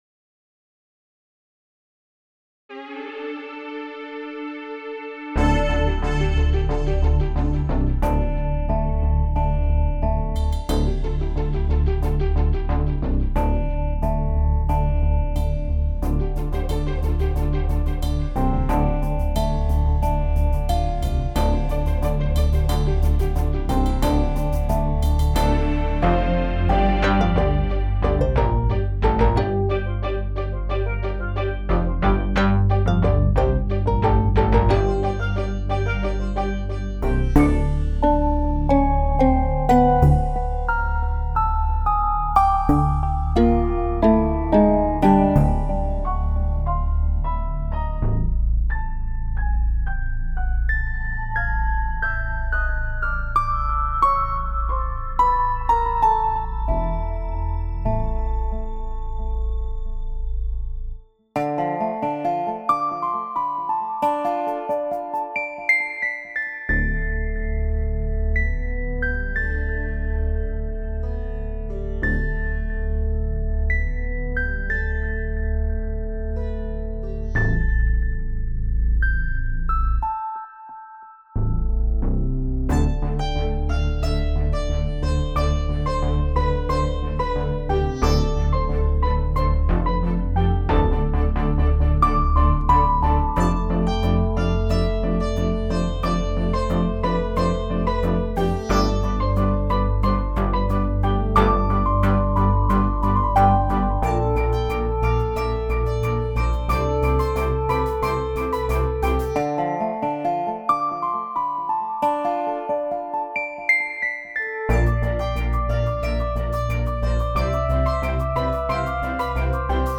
Sound icon I love ignoring the conventional cultural context of instruments and just combining them based on sound, which is how I ended up layering steel drums with a sitar.